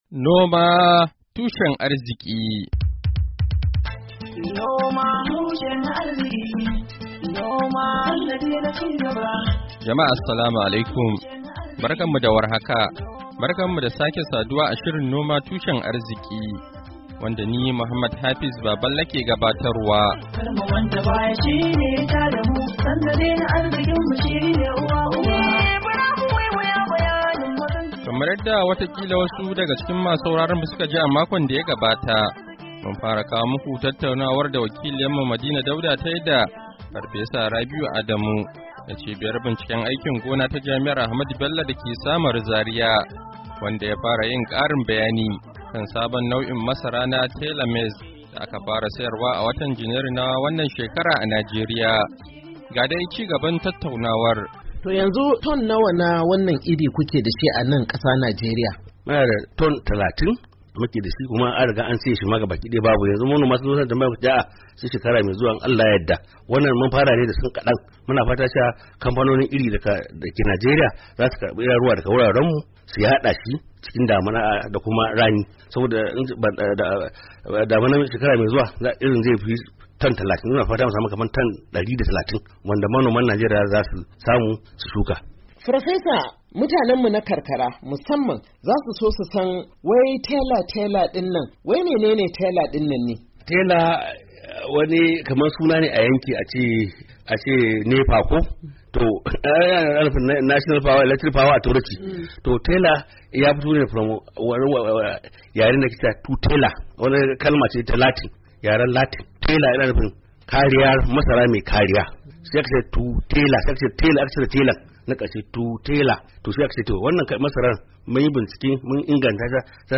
Shirin Noma Tushen Arziki na wannan makon, zai kawo muku ci gaban tattaunawa da wani masani kan batun sabon nau'in irin masara na Tela Maize da aka fara sayarwa a kasuwa cikin watan Janairu na wannan shekarar da ya janyo ce-ce-kuce. Masana sun ce sabon irin bashi da illa kuma yana jurewa fari da kwari.